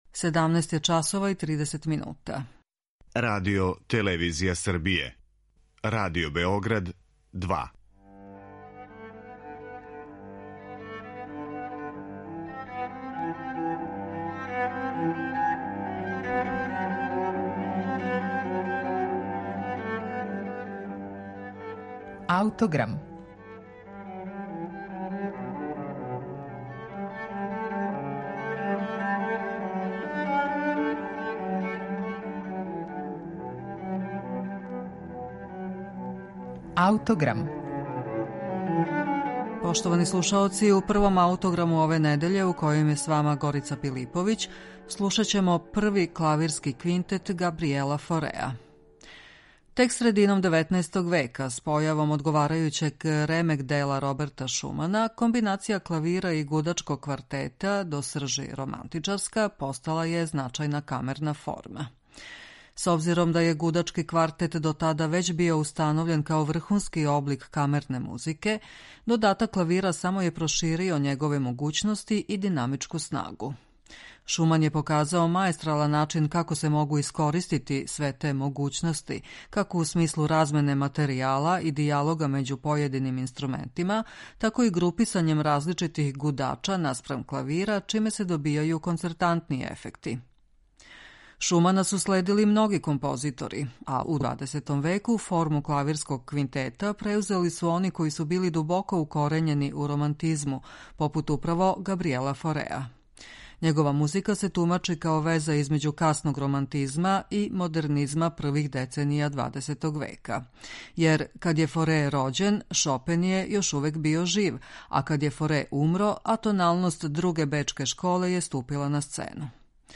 Његова музика се тумачи као веза између касног романтизма и модернизма првих деценија ХХ века.
Међутим, током последњих 20-ак година живота Форе је имао проблема са слухом и то је резултирало музиком специфичног карактера - повремено уздржаног и неухватљивог, а повремено турбулентног и страственог.